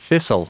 Prononciation du mot fissile en anglais (fichier audio)
Prononciation du mot : fissile